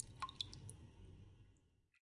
water drop03
描述：handmade water drops on water of a sinktwo small dropsrecorded with sony MD recorder and stereo microphone
标签： drops handmade water
声道立体声